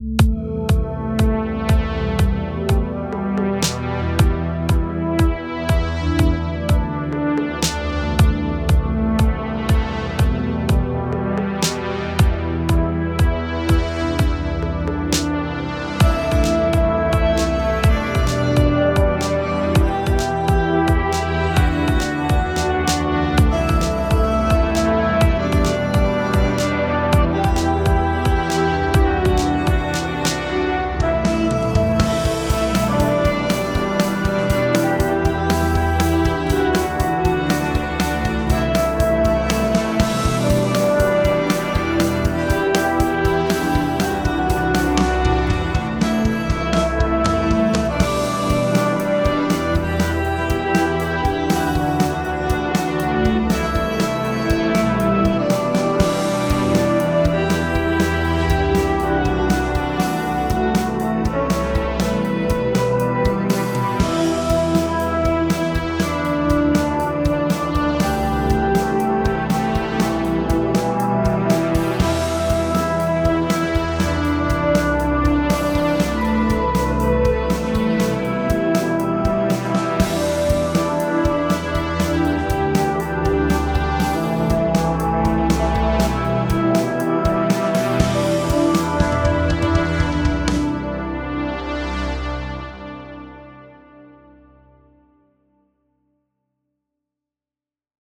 Je compose de la "MAO"* sur Mac
*(Musique Assistée par Ordinateur)
Musiques composées avec GarageBand